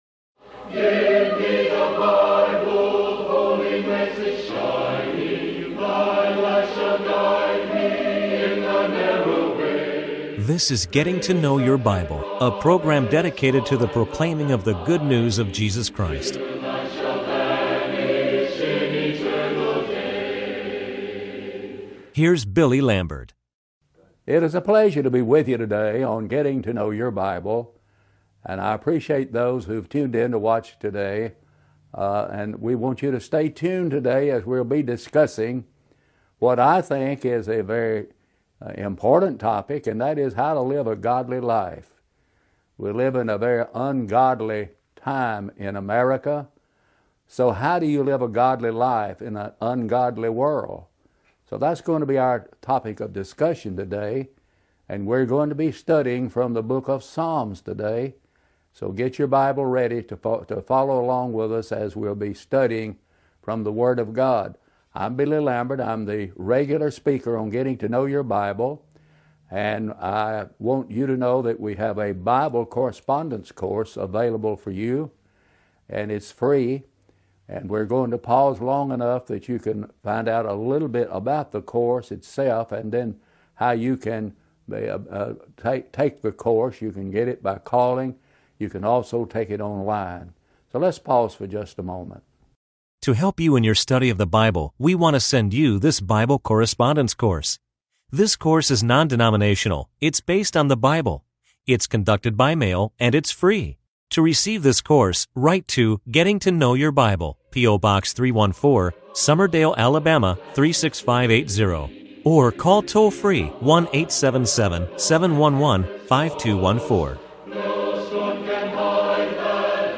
Bible Study
Talk Show